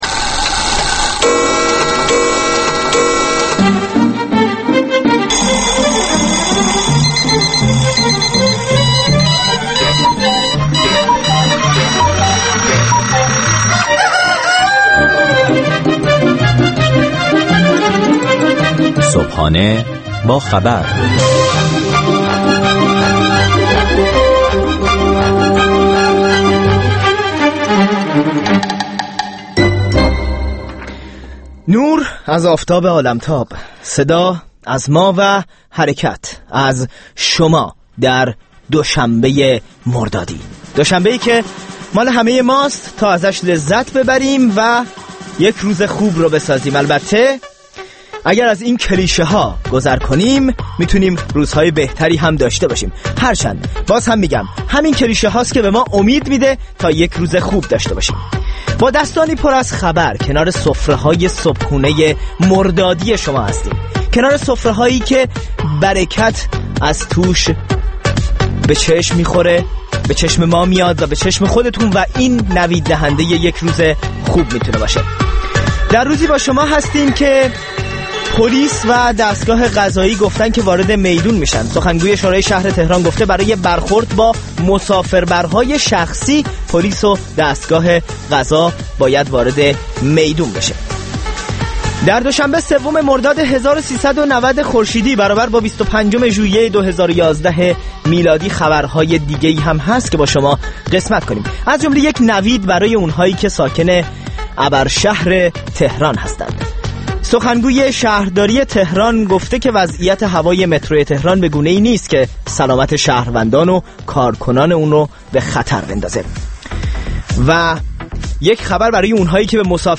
بامداد خود را با مجله «صبحانه با خبر» راديو فردا آغاز کنيد. گزارشگران راديو فردا از سراسر جهان، با تازه‌ترين خبرها و گزارش‌ها، مجله‌ای رنگارنگ را برای شما تدارک می‌بينند.